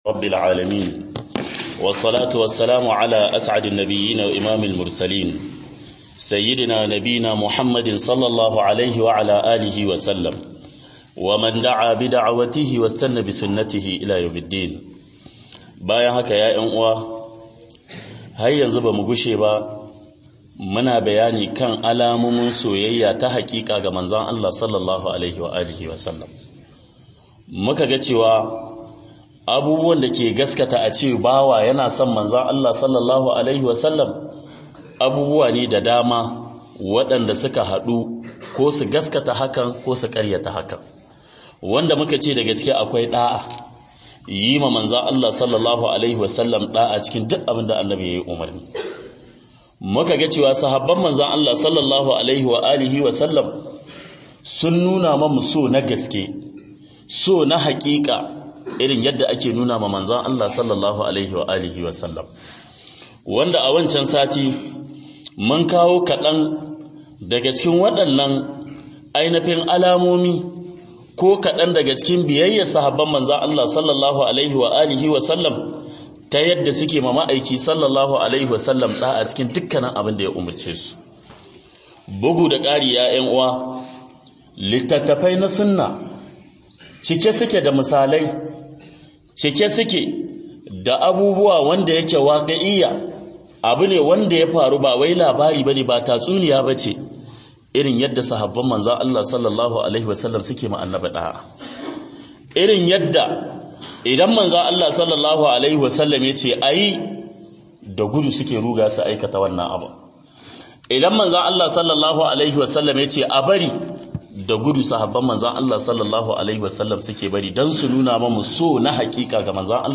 GASKIAR SON-MANZO-ALLAH-S.A.W - HUDUBA